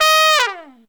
Index of /90_sSampleCDs/Zero-G - Phantom Horns/TRUMPET FX 3